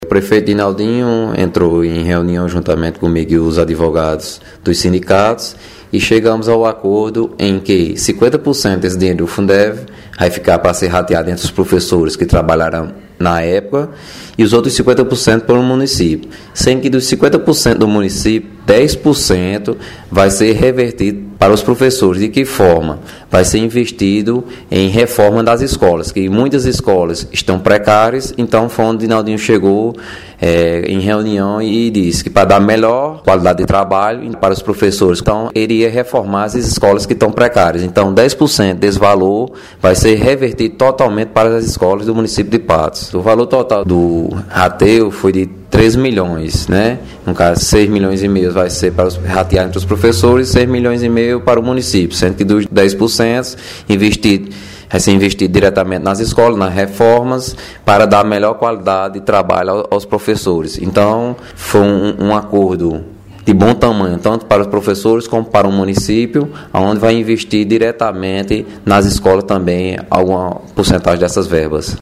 O procurador geral do município de Patos, Phillipe Palmeira, em entrevista na manhã desta terça-feira, dia 21, falou sobre o acordo que levará ao desbloqueio de 13 milhões em recursos do Fundo de Manutenção e Desenvolvimento do Ensino Fundamental e de Valorização do Magistério (FUNDEF) advindos de uma ação antiga na justiça.
PROCURADOR GERAL DO MUNICÍPIO – PHILLIPE PALMEIRA –